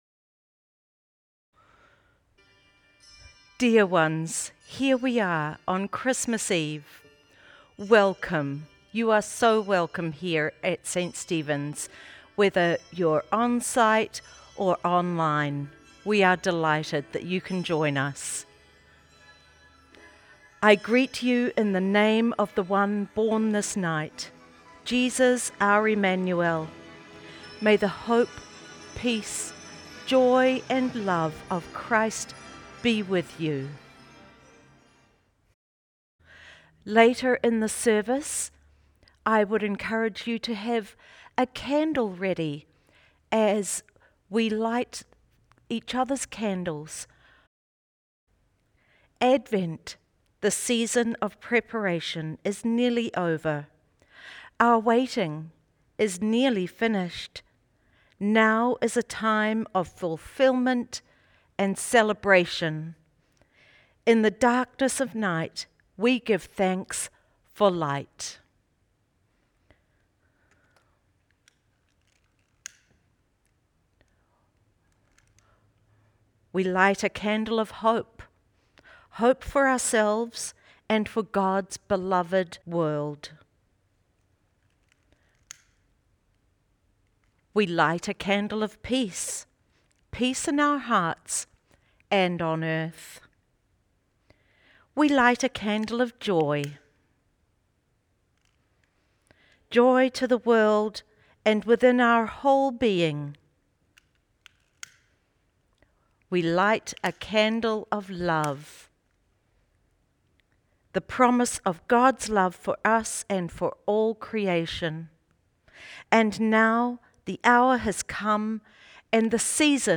Christmas Eve Candlelight Service
In place of a sermon, we have included “Lessons and Carols” where the story of Jesus’ birth is given through a series of Bible readings (lessons) and songs (carols).
Following the blessing you are again invited to join in the singing of either a contemporary song or a traditional hymn.